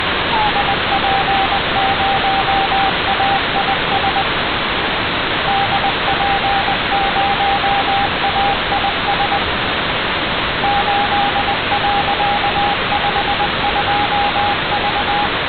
received the CW signal of AISat-1 on 437.511 MHz.